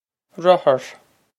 rothar ruh-her
This is an approximate phonetic pronunciation of the phrase.